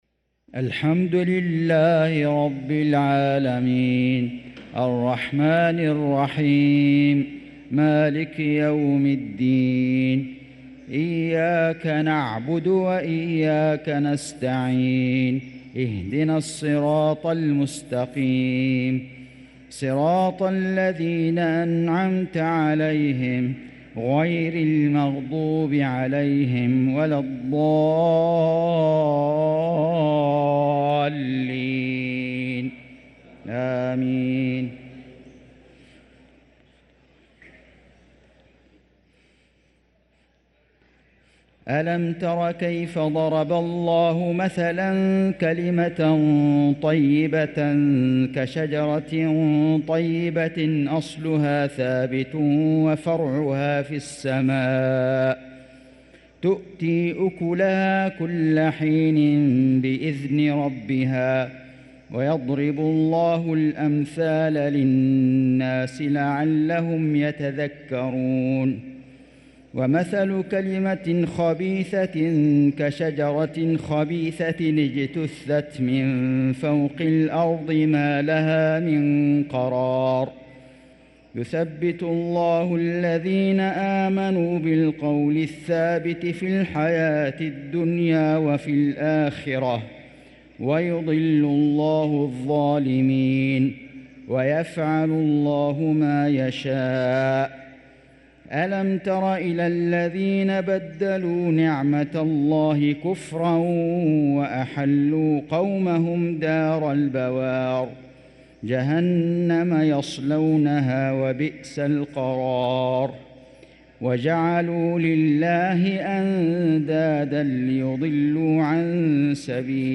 صلاة العشاء للقارئ فيصل غزاوي 7 رجب 1445 هـ
تِلَاوَات الْحَرَمَيْن .